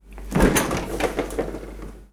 Abrir la puerta de un frigorífico 2
Cocina
Sonidos: Acciones humanas
Sonidos: Hogar